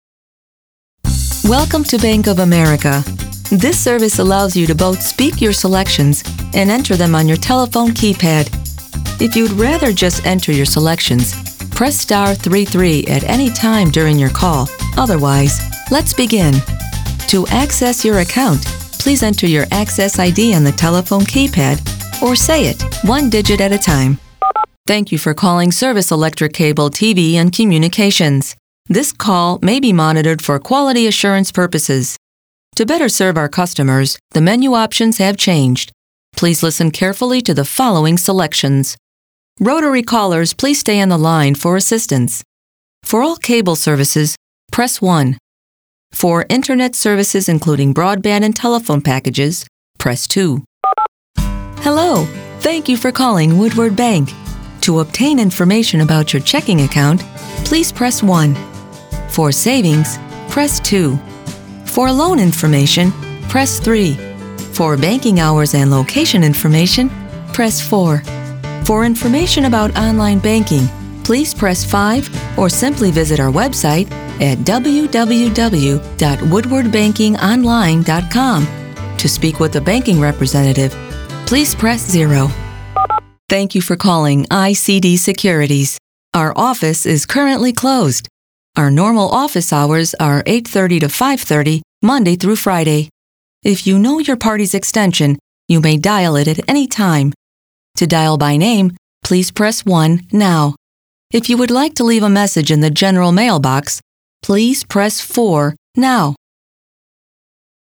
Her voice is natural, believable and engaging, perfect for a broad range of projects. Her voice is versatile, it can be friendly, fun, fresh, approachable, sincere, strong, intimate and sensual.
believable,honest,confident, warm,professional,smooth, sophisticated,fun,friendly, upbeat,trustworthy mom,sassy,sultry
middle west
Sprechprobe: Sonstiges (Muttersprache):